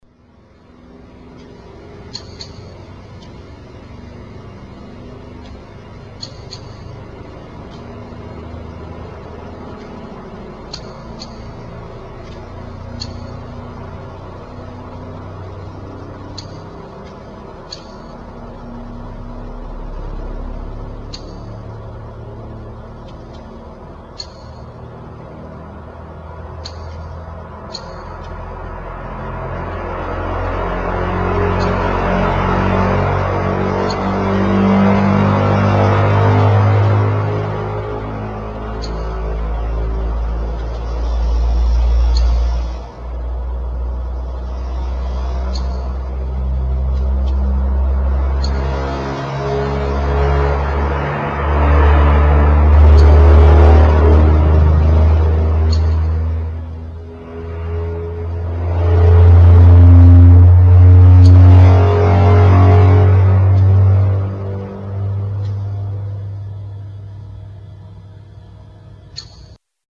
Marsquake